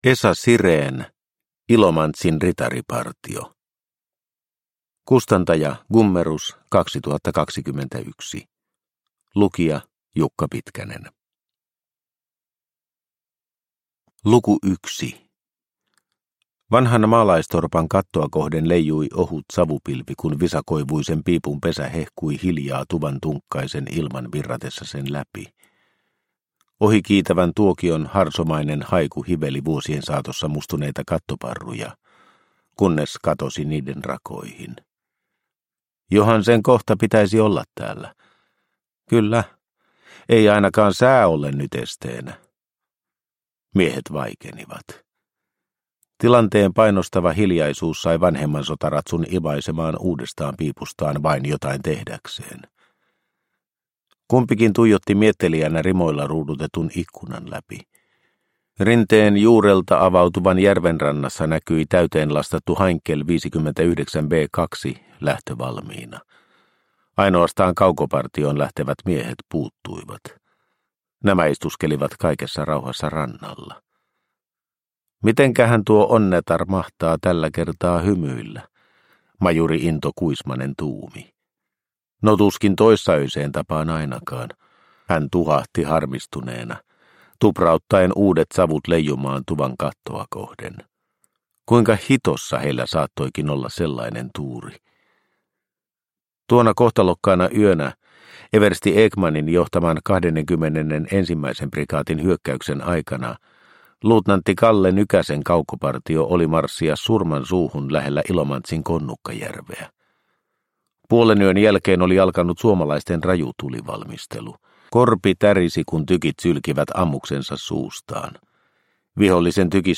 Ilomantsin ritaripartio – Ljudbok – Laddas ner